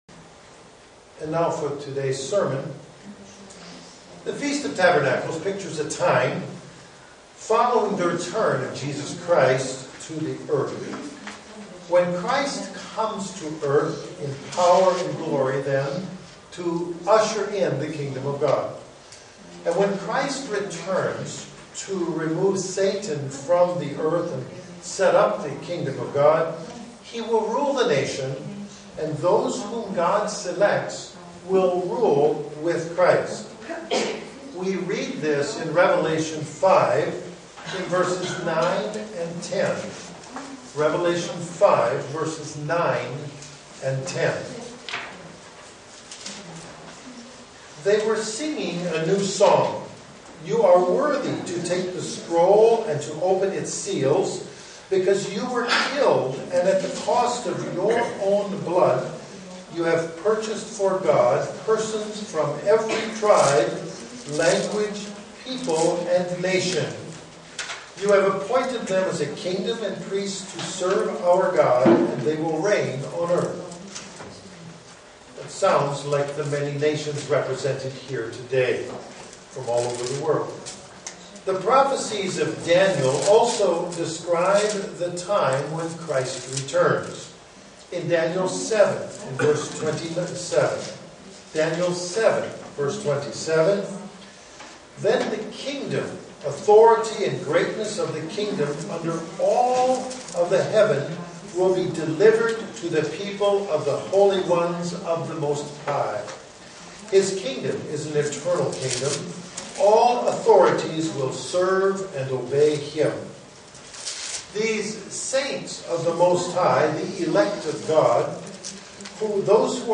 Day one of the Feast of Tabernacles in Estonia SEE VIDEO BELOW
Print Day one of the Feast of Tabernacles in Estonia SEE VIDEO BELOW UCG Sermon Studying the bible?